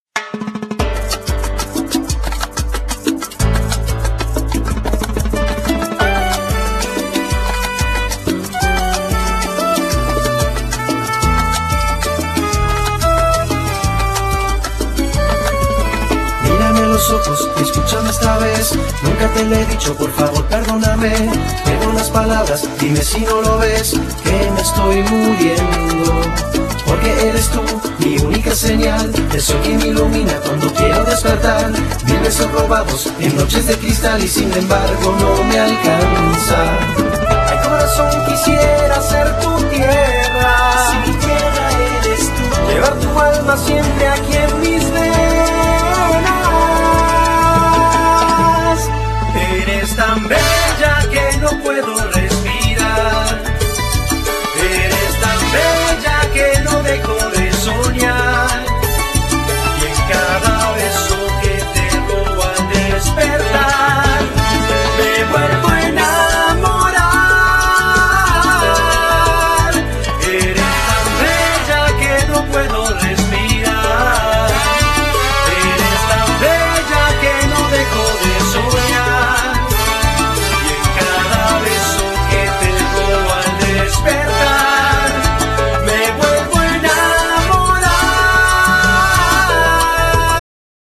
Genere : Latin